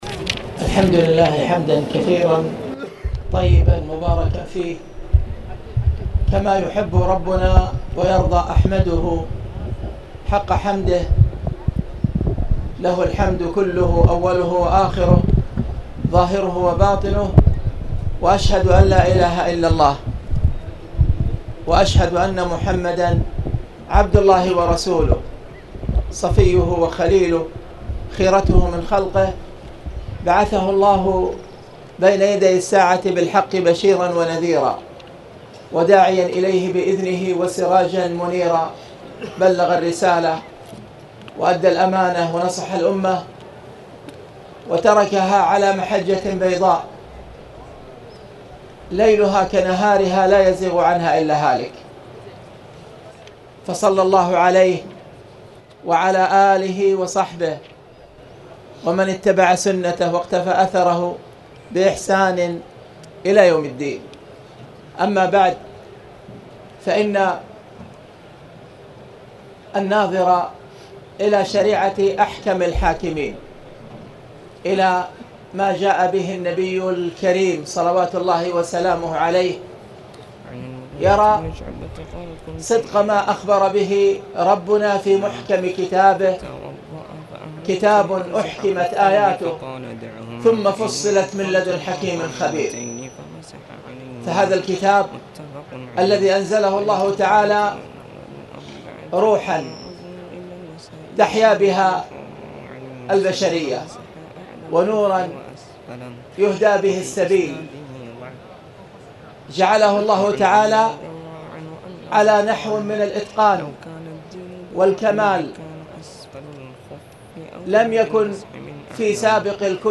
تاريخ النشر ٢ جمادى الأولى ١٤٣٨ هـ المكان: المسجد الحرام الشيخ